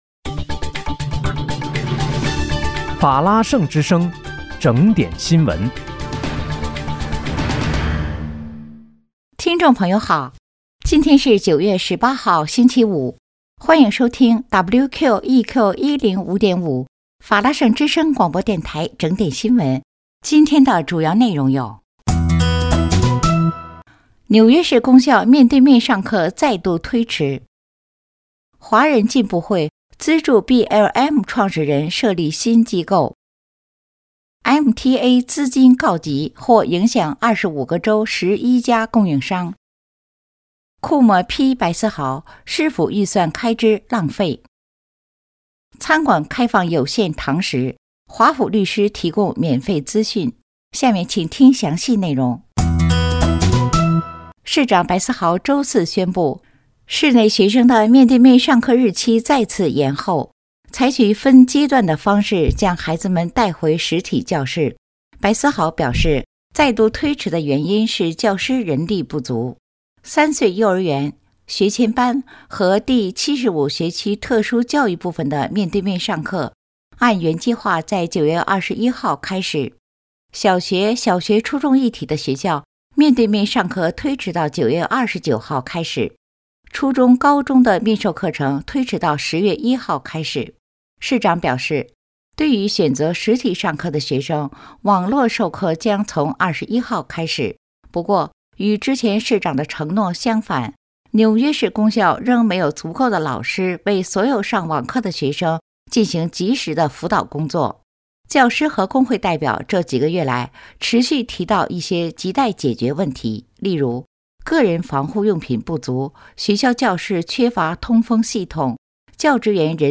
9月18日（星期五）纽约整点新闻
听众朋友好！今天是9月18号，星期五，欢迎收听WQEQ105.5法拉盛之声广播电台整点新闻。